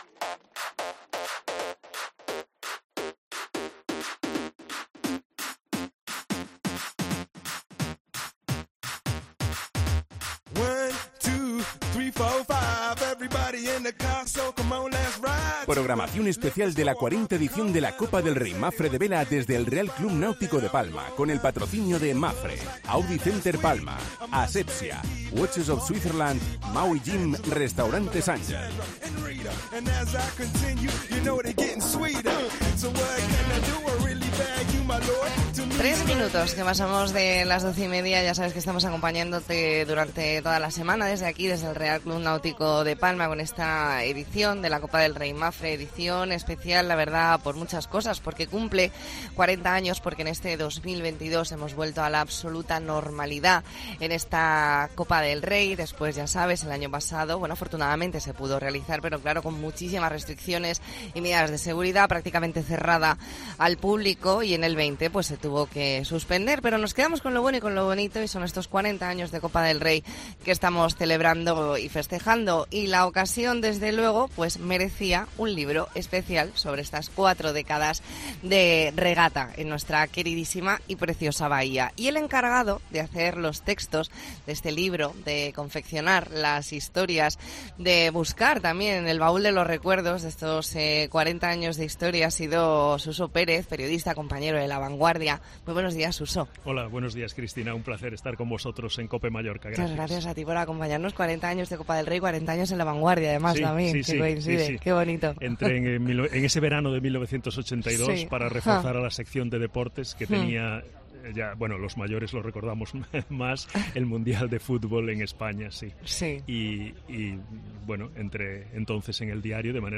AUDIO: Especial La Mañana en COPE Más Mallorca desde el RCNP con motivo de la 40 Copa del Rey Mapfre
Entrevista en La Mañana en COPE Más Mallorca, miércoles 3 de agosto de 2022.